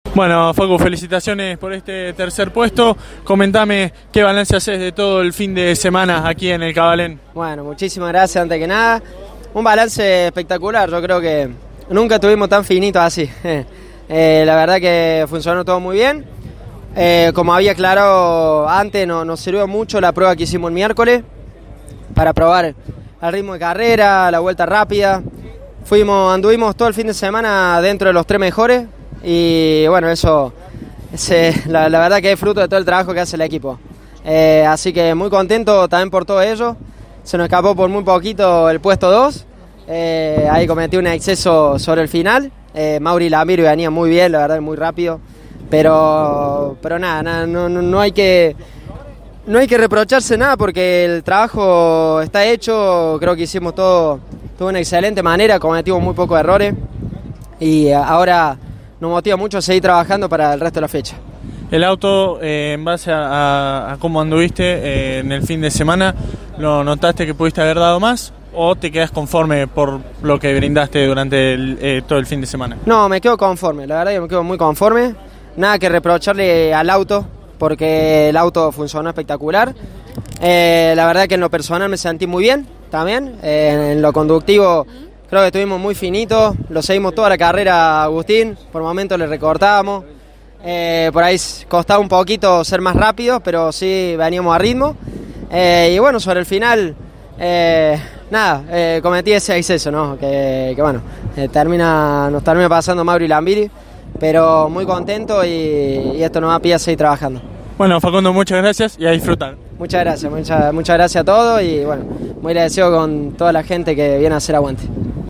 LA PALABRA DE LOS TRES DEL «ESTRADO DE HONOR» DE LA FINAL DE LA CLASE 3 DEL TN EN CÓRDOBA